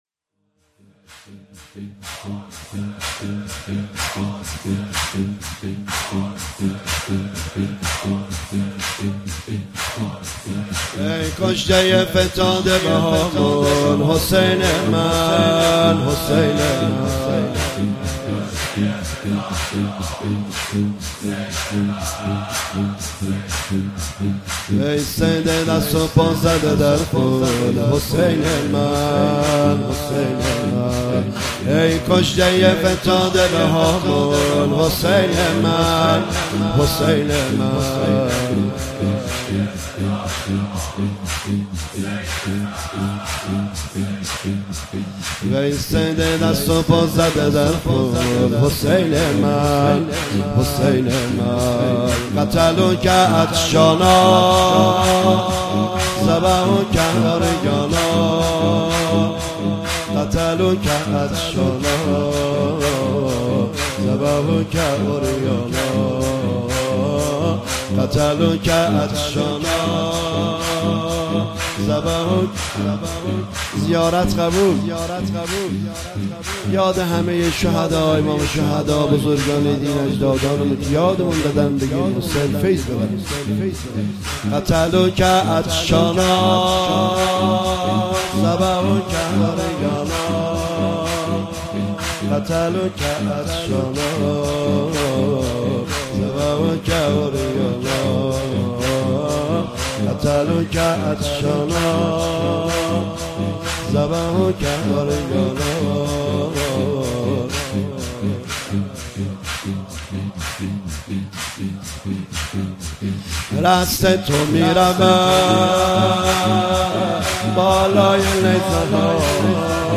شب چهارم محرم97 هیئت مکتب ‌الحسین(ع)